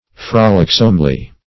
frolicsomely - definition of frolicsomely - synonyms, pronunciation, spelling from Free Dictionary
-- Frol"ic*some*ly, adv. -- Frol"ic*some*ness, n.